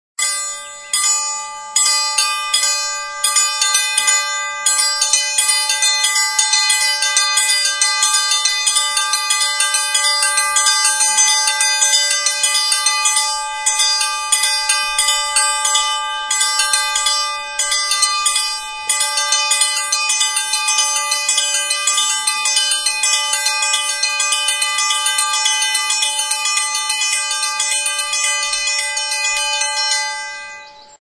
Idiophones -> Struck -> Directly
Hernani, 2001. Tobera jotzaile talde honek burni ziri hauek erabili zituzten.
TXALAPARTA; TOBERAK
Soinu-tresna honek honako osagaiak ditu: palanka, hau kolpeatzeko altzairuzko lau burnitxoak eta palanka zintzilikatzeko bi muturretan soka bana.